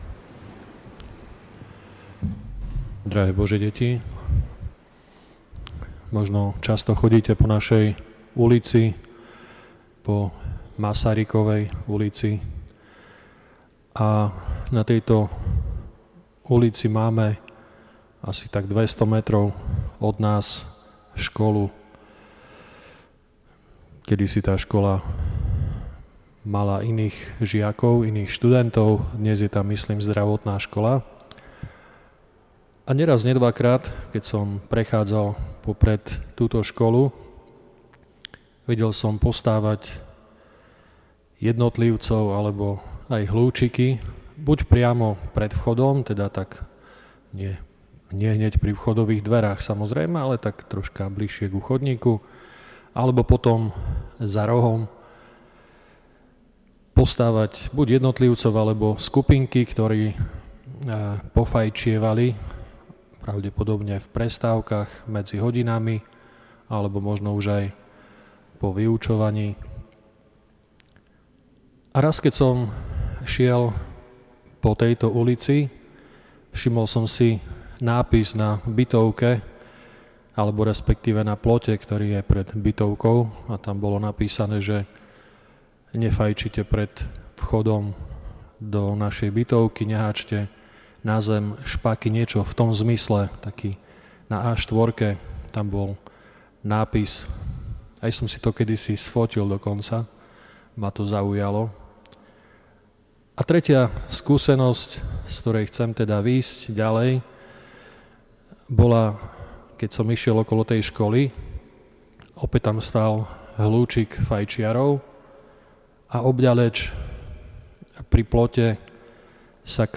V dnešný deň si pripomíname spomienku nášho blahoslaveného Metoda.
Večernej svätej liturgii predsedal vladyka Milan Chautur, CSsR spolu so spolubratmi z kláštora redemptoristov.
Príhovor